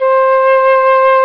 Flute Mid Sound Effect
Download a high-quality flute mid sound effect.
flute-mid.mp3